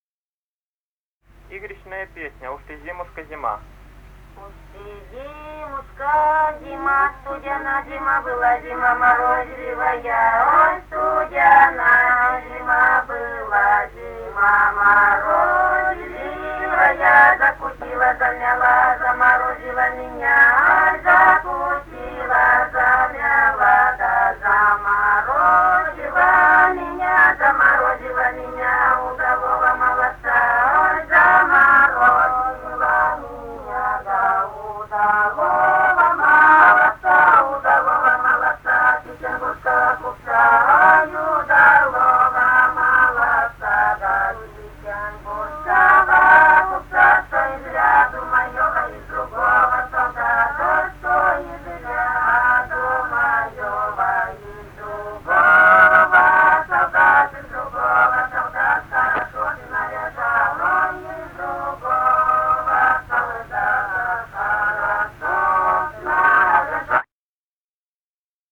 Русские народные песни Красноярского края.
«Уж ты, зимушка-зима» (игрищная). с. Троица Пировского района.